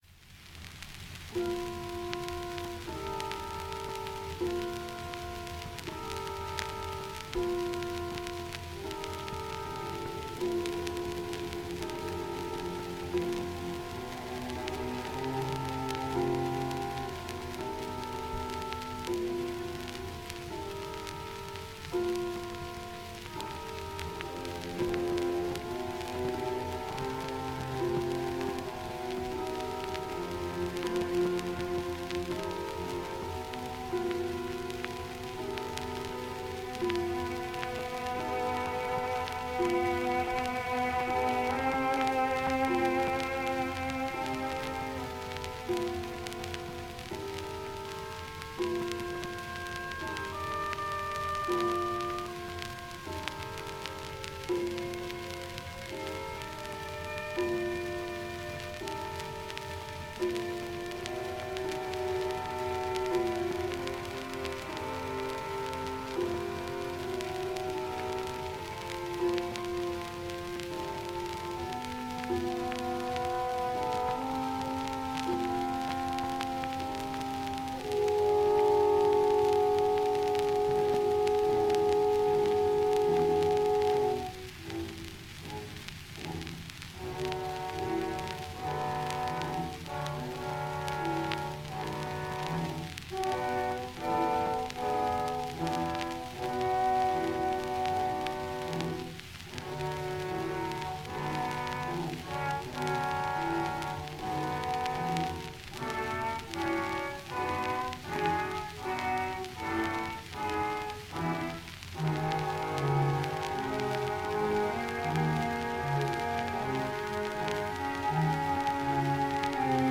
다장조, 4/4박자.[96] 특정한 형식에 얽매이지 않고 자유로이 곡상을 풀어가는 교향시의 성격이다.
목관이 이 A음형을 반복하고, 점차 현악기군과 목관이 더해지고 금관이 지속음을 내면서 멎는 듯하다가, 본격적인 이야기가 시작되는 듯한 느낌으로 첼로와 더블베이스가 행진곡 풍의 리듬을 피치카토로 연주하기 시작한다.
오르간의 지속음과 하프와 목관이 어우러지는 부드러운 금관의 see-saw 음형이 명상적인 느낌을 이끌어 내며 천천히 음색을 변화시켜 간다. 마침내 모든 욕심으로부터 초연해진 듯이, 음악은 서서히 높은 곳을 향해 사라진다.
조성은 C장조이지만, 3도에 플랫이 붙는 등 불안정하다. C장조 위에 부가 6화음과 7화음이 자주 사용된다.
하프와 플루트가 장식하면 신비로운 고요에 싸여 간다. 이윽고 오르간 페달의 울림이 더해지며, 조용히 곡을 마친다.